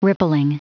Prononciation du mot rippling en anglais (fichier audio)
Prononciation du mot : rippling